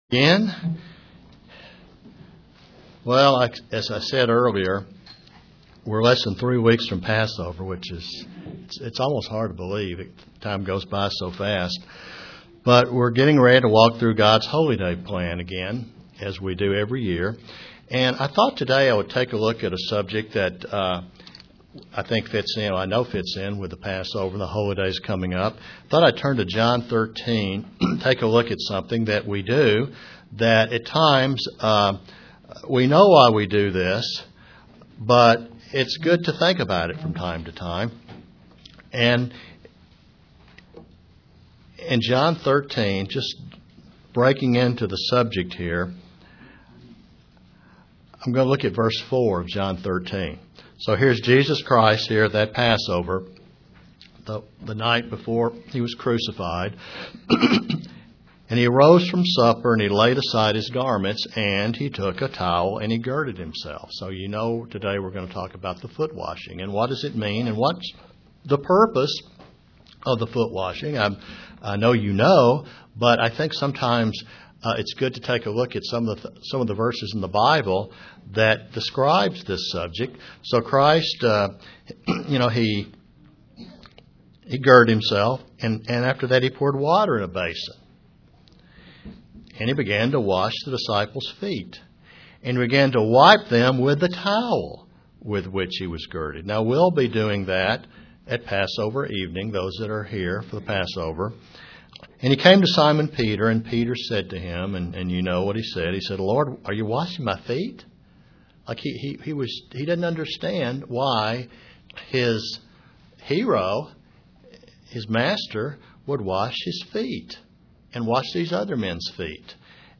When Jesus instituted the foot washing ceremony at Passover He was instructing Christian in a vital part of Christianity. (Presented to the Kingsport TN, Church)